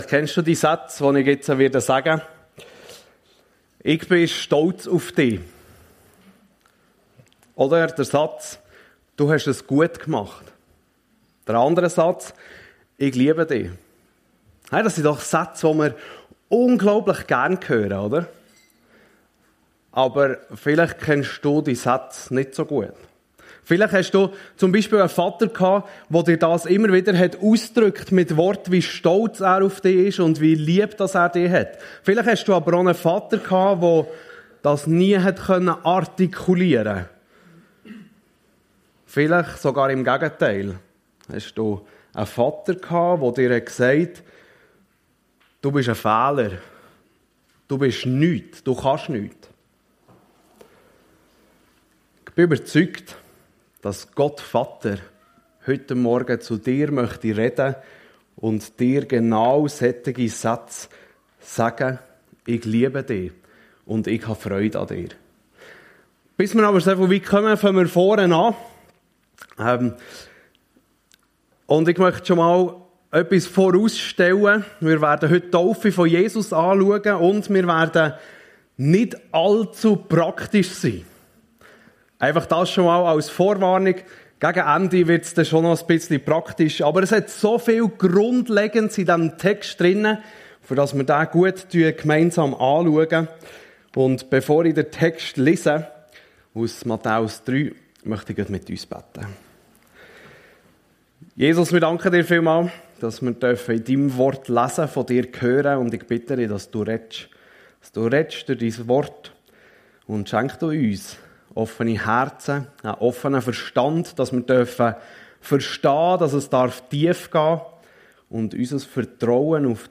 Die Taufe Jesu ~ FEG Sumiswald - Predigten Podcast